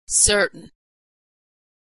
Voiceless /t/
When /t/ is stressed, it has a puff of air.
When /t/ is not stressed, it does not have a puff of air.